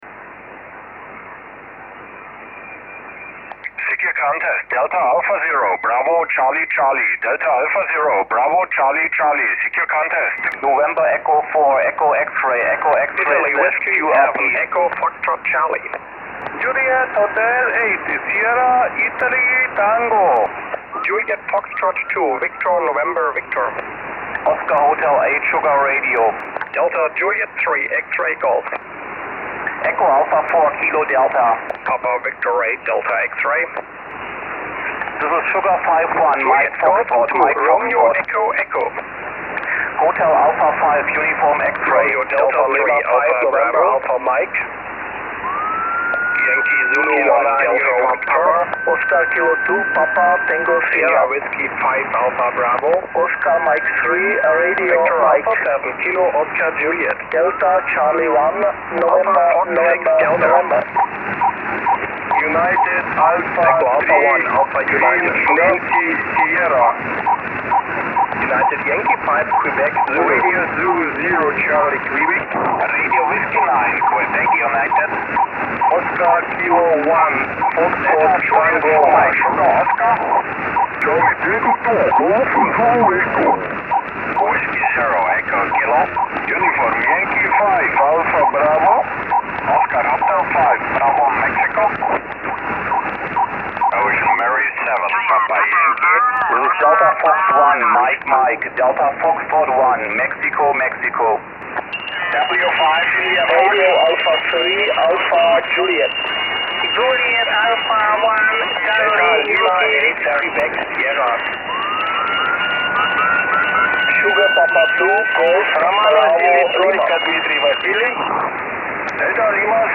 Also wurden zwei PileUps über 3 Minuten simuliert (alles selbstgesprochen – die japanischen Rufzeichen sollen am meisten Spaß gemacht haben).